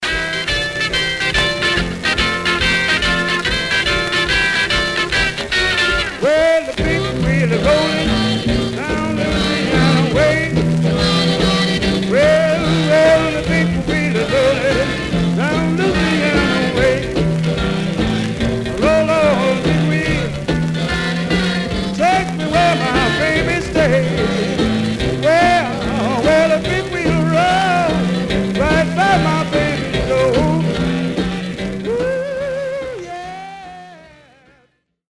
Surface noise/wear Stereo/mono Mono
Rythm and Blues